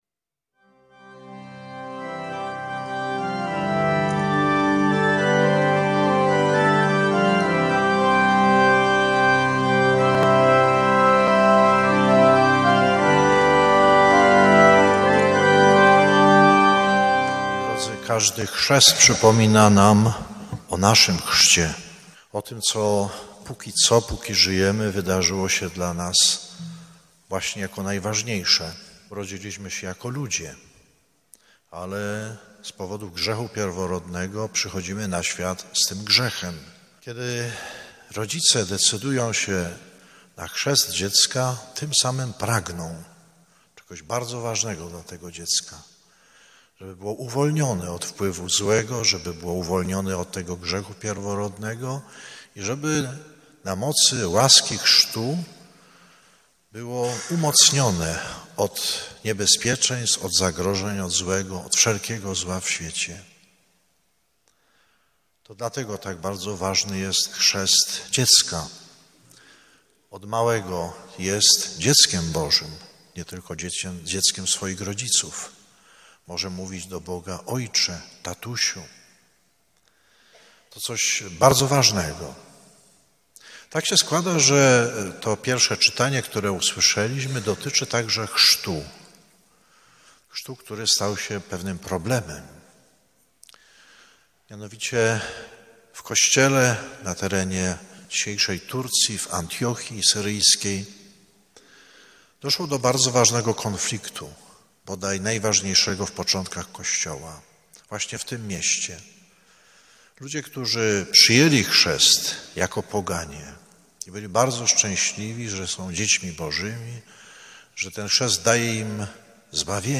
W parafii św. Karola Boromeusza w Koszarawie gościł 25 maja 2025 r. bp Roman Pindel.